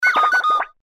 Shake.mp3